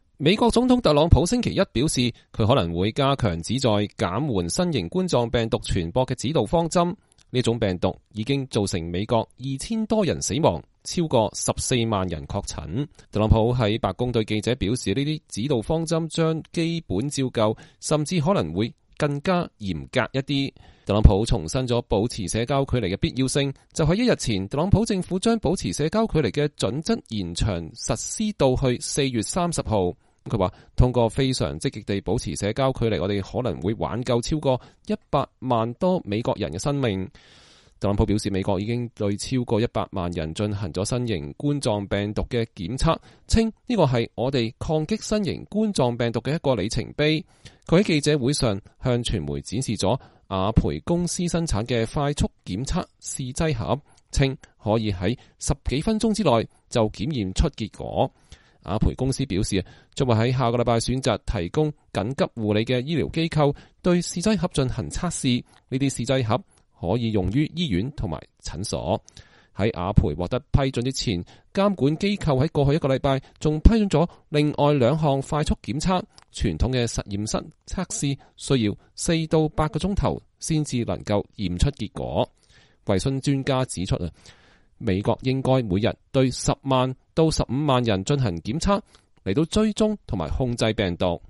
美國總統特朗普在白宮玫瑰園舉行的“新型冠狀病毒特別工作組”新聞發布會上回答記者提問（2020年3月30日）。